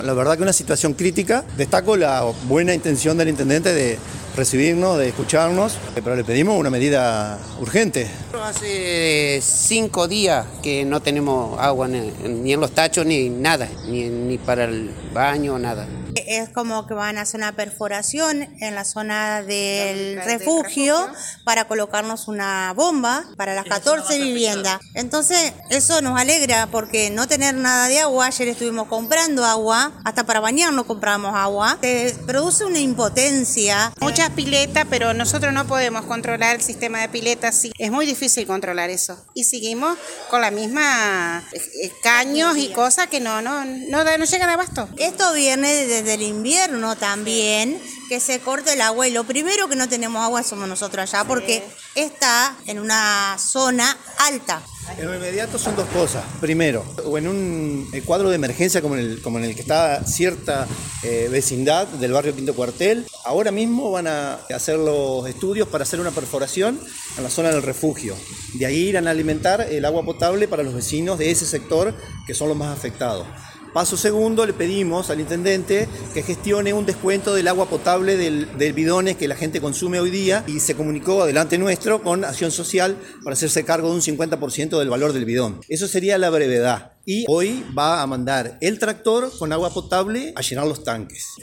Tras dicha reunión, los vecinos dialogaron con la prensa y destacaron una de las iniciativas que llevará a cabo el municipio: la realización de una perforación para poder brindar agua a los vecinos de las 14 viviendas, quienes son los más afectados ante el exceso de consumo de agua de la ciudad.